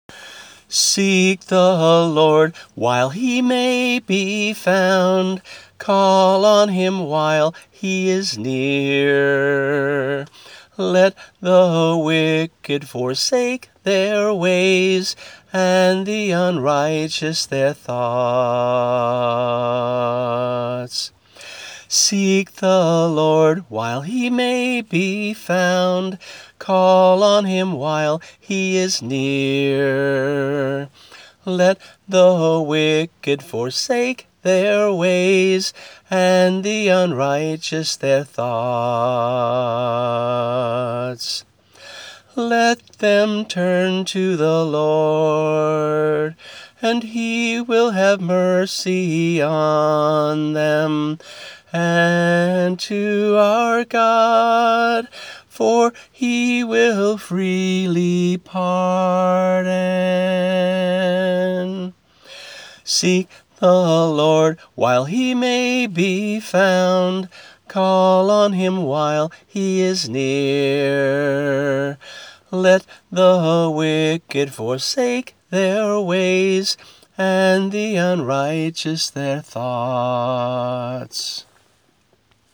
voice only